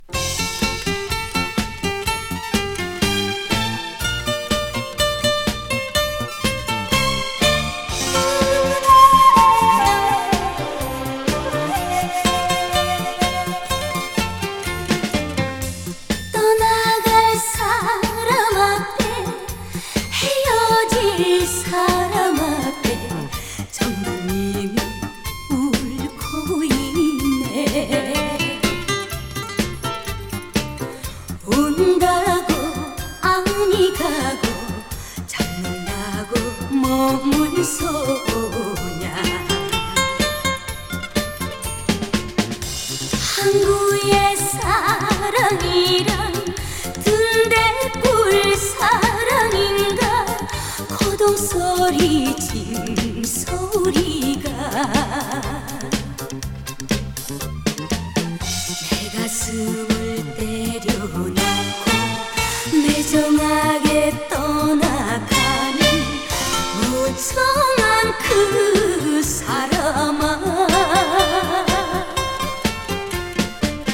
アルバム通して、ドラムがタイトで、珍シンセがナイスです！